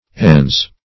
Ens \Ens\ ([e^]nz), n. [L., ens, entis, a thing. See Entity.]